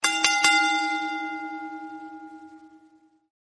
Wwe Ring Bell Soundboard: Play Instant Sound Effect Button